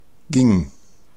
Ääntäminen
Vaihtoehtoiset kirjoitusmuodot gieng Ääntäminen Tuntematon aksentti: IPA: /ɡɪŋ/ IPA: /gɪŋ/ Haettu sana löytyi näillä lähdekielillä: saksa Käännöksiä ei löytynyt valitulle kohdekielelle. Ging on sanan gehen imperfekti.